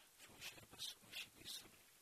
Pronunciation: ʃu:ʃepəʃ uʃi:pi:səm